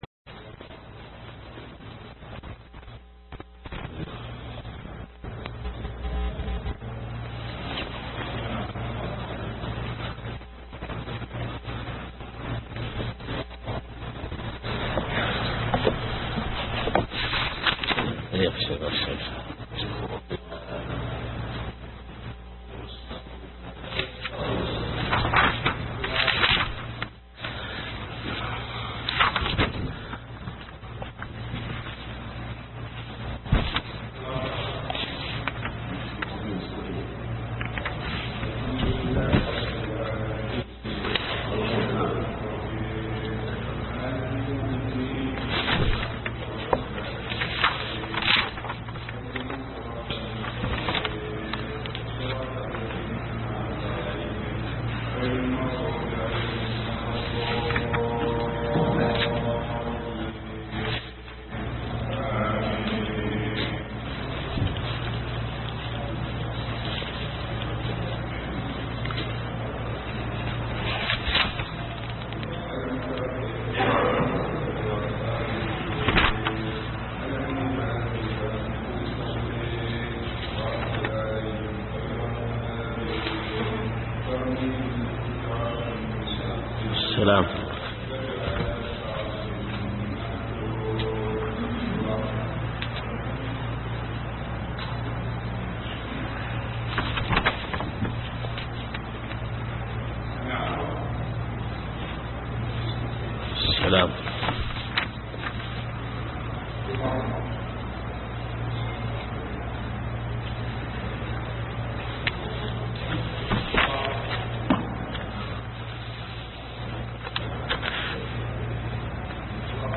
شرح الفروق والتقاسيم البديعة النافعة لابن سعدي الدرس (3)